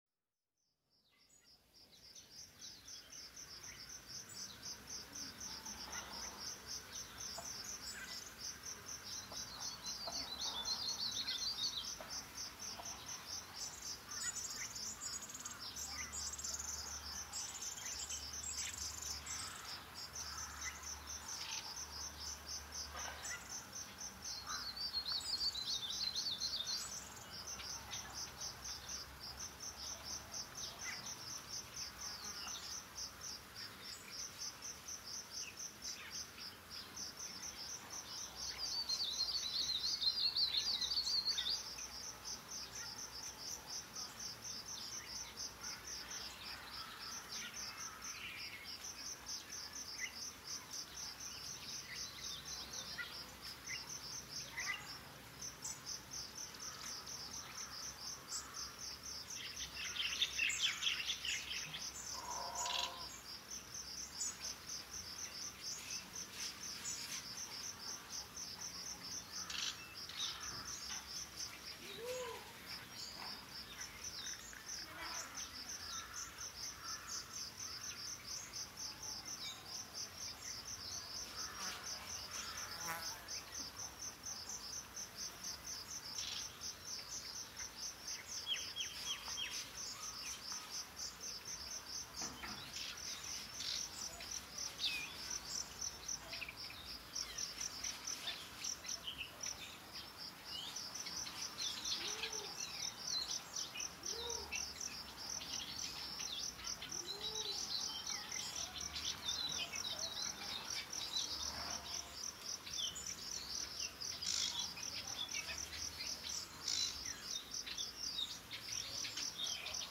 Wat Phothivihan ASMR
asmr-nature-4K-Thai-Temple-ASMR-with-Natural-Nature-Sounds.mp3